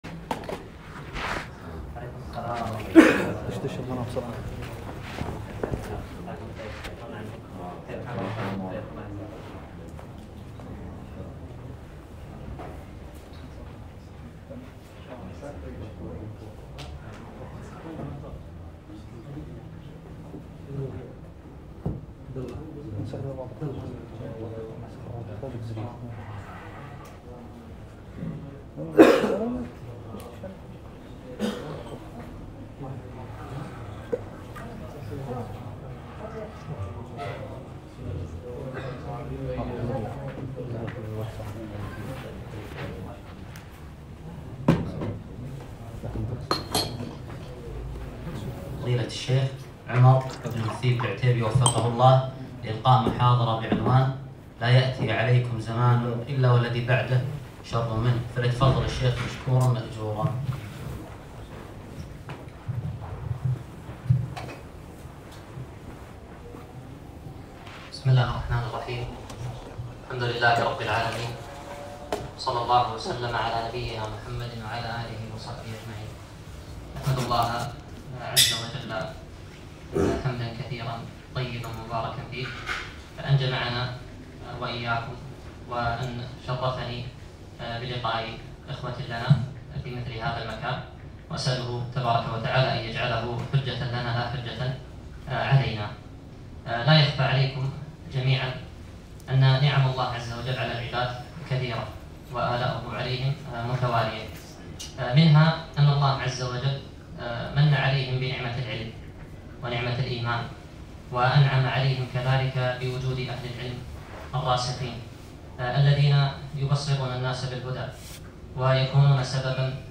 محاضرة - ( لا يأتي عليكم زمان إلا والذي بعده شر منه )